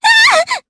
Aisha-Vox_Damage_jp_03.wav